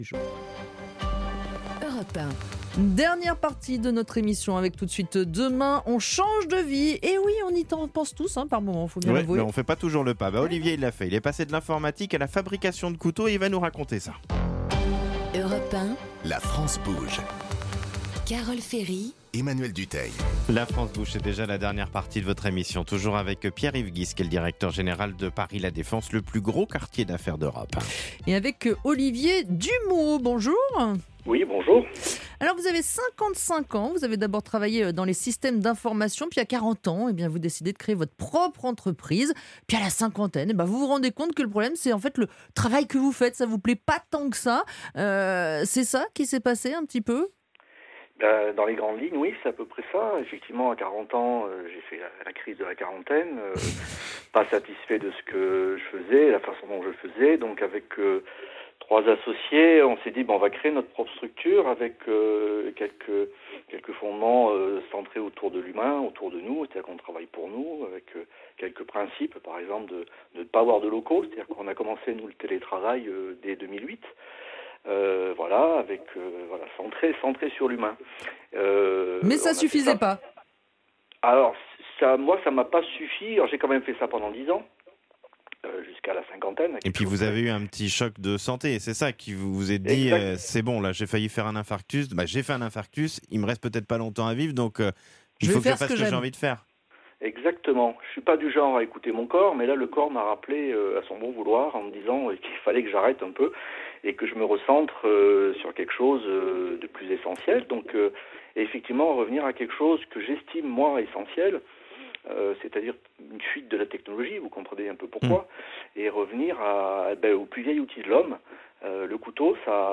J'ai eu la chance d'être interviewé dans le cadre de cette émission, afin de raconter "rapidement" mon histoire, chapitre "Demain on change de vie".